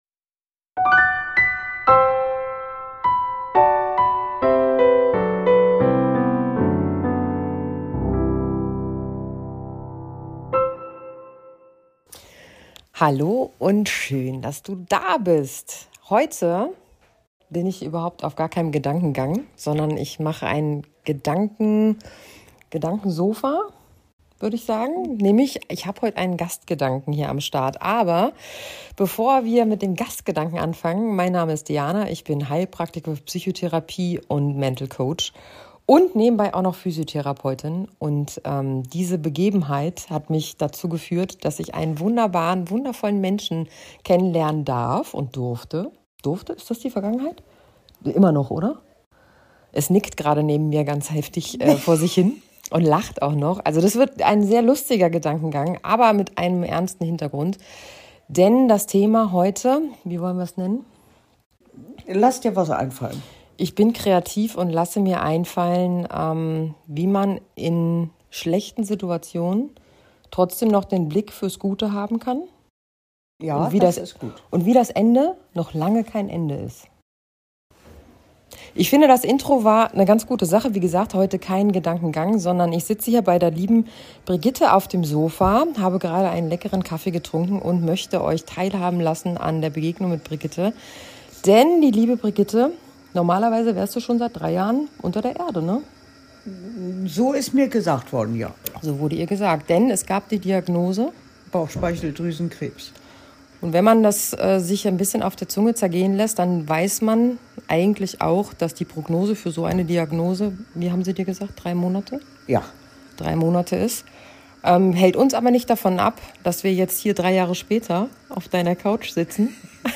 In dieser emotionalen Episode berichtet eine Frau über einen Moment, der ihr Leben auf den Kopf stellte: Die Diagnose Bauchspeicheldrüsenkrebs – mit einer düsteren Prognose.